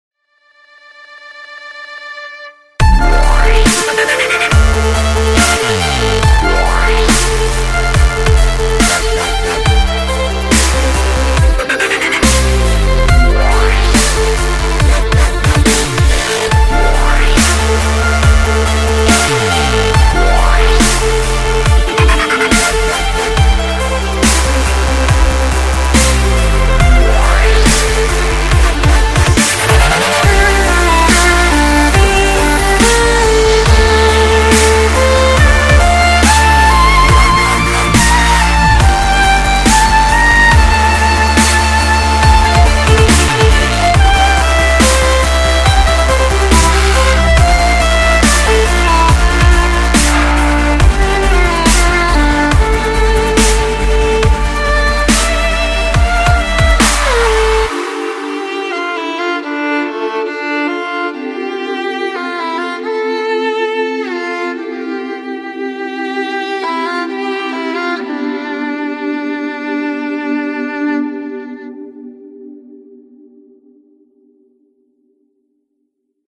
громкие
скрипка
энергичные
Красивый, энергичный звонок в твоем телефоне.